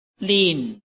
臺灣客語拼音學習網-客語聽讀拼-海陸腔-鼻尾韻
拼音查詢：【海陸腔】lin ~請點選不同聲調拼音聽聽看!(例字漢字部分屬參考性質)